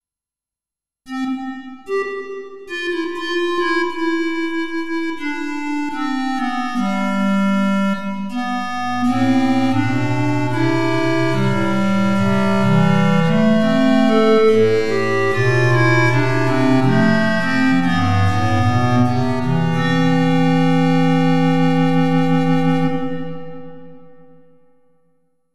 A short example of the clarinet sound with a impulse reverb on it: